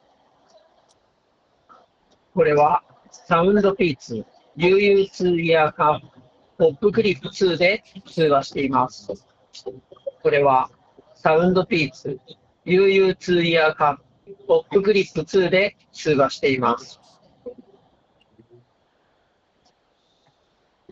通話品質・ノイズキャンセリング
周囲に喧騒音を大きく流した状態で通話を録音して検証しています。
完全な無音にはなりませんが、雑踏の中でも声がしっかり相手に届く優秀な性能でした。
自分の声が若干機械音っぽくなるものの気になるレベルではなく、普通の通話はもちろんWeb会議にも十分使えるマイク性能です。
popclip-2-voice.m4a